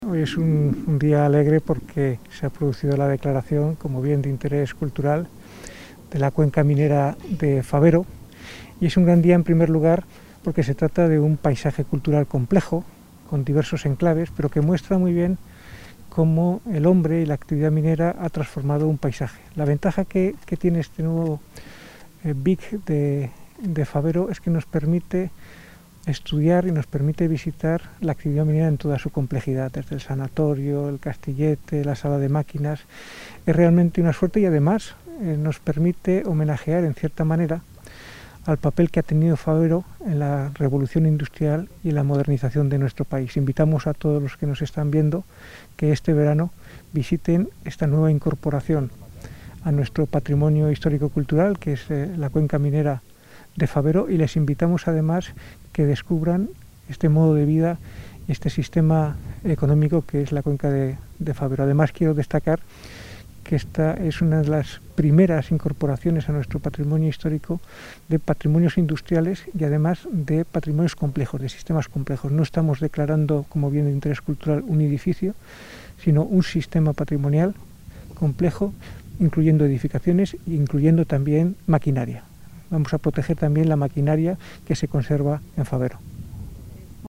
Audio viceconsejero.